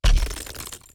step2.ogg